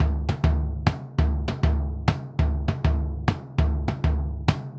Bombo_Baion_100_2.wav